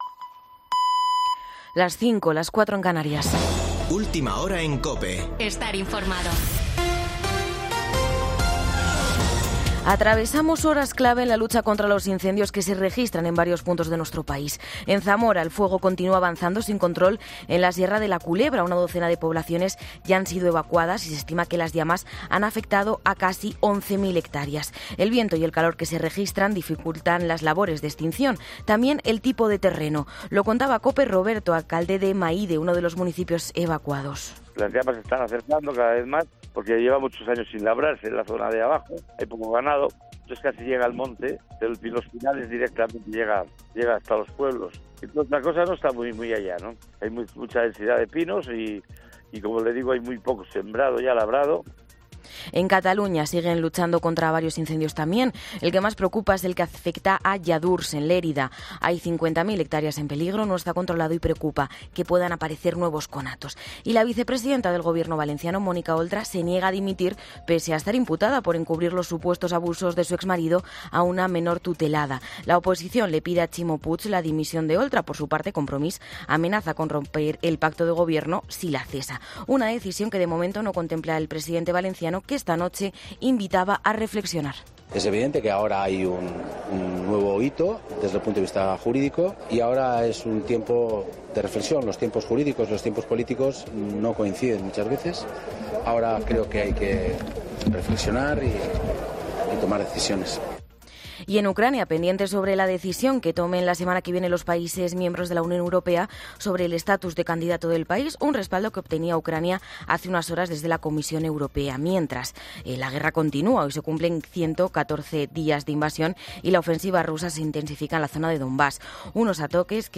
AUDIO: Boletín de noticias de COPE del 18 de junio de 2022 a las 05.00 horas